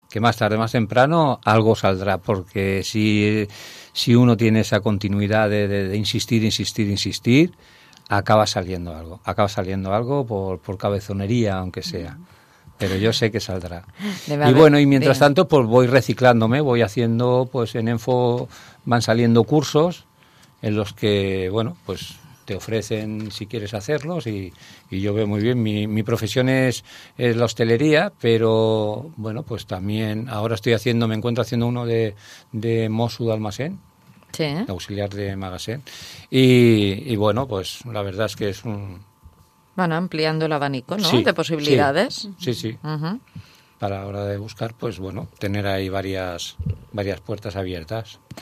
Aquesta setmana volem compartir amb vosaltres un tall del “Posa’t les piles” de Ràdio Mollet d’aquest dilluns on es parla de la perseverància en la recerca de feina tenint clar l’objectiu professional, i de la importància del reciclatge formatiu per obrir-se noves portes laborals.